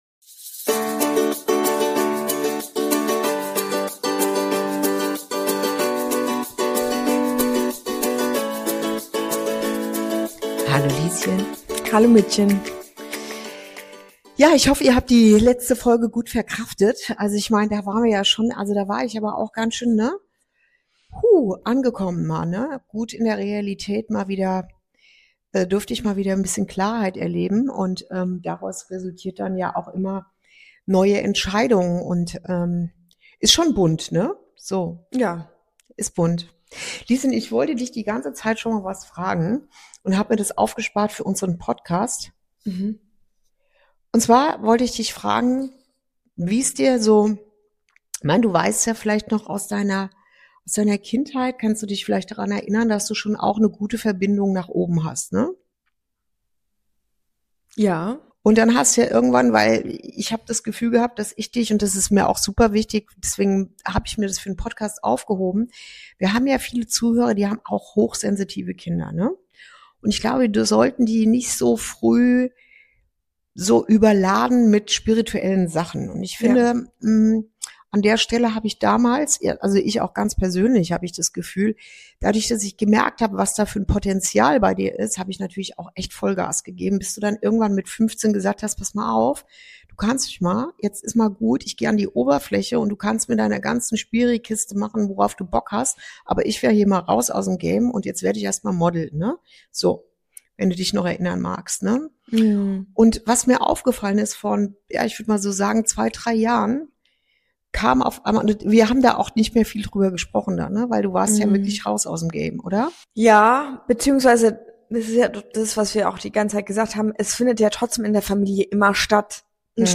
Folge 4: Berufung leben – Wenn der Weg sich von selbst entfaltet ~ Inside Out - Ein Gespräch zwischen Mutter und Tochter Podcast